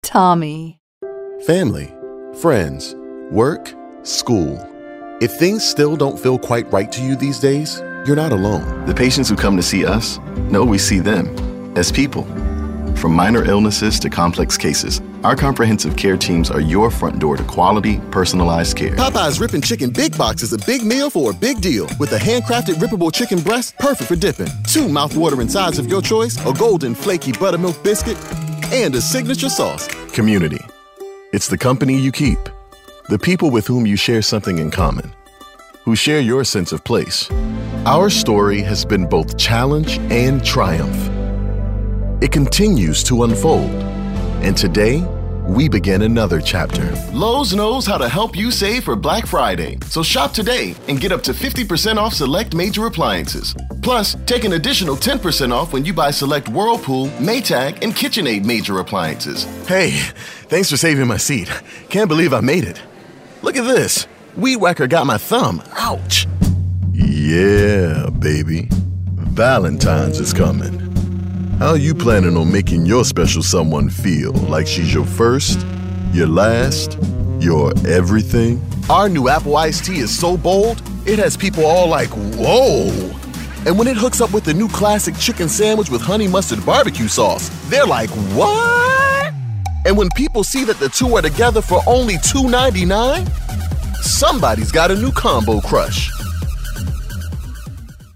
From Conversational to deadpan, and everything in between, his voice fits the bill.
Showcase Demo